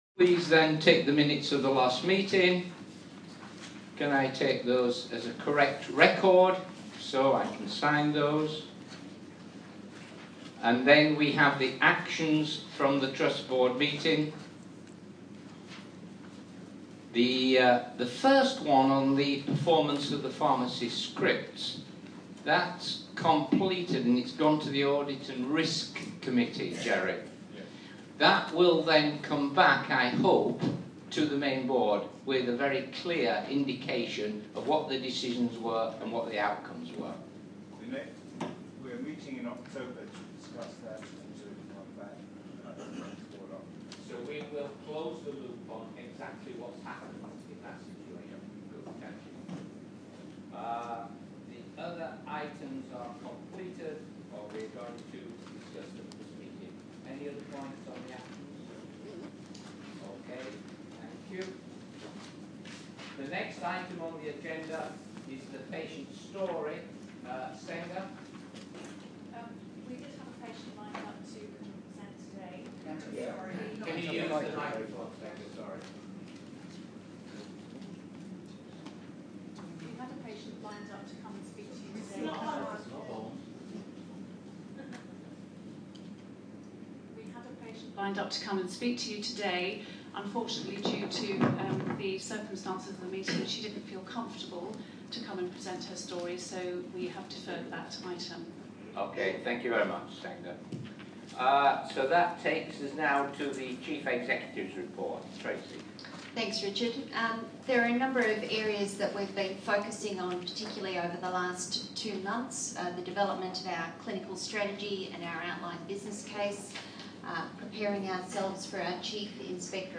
Imperial Trust Public Meeting, at Hammersmith Hospital 30/07/2014